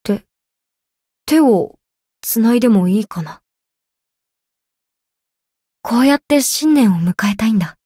灵魂潮汐-南宫凛-春节（摸头语音）.ogg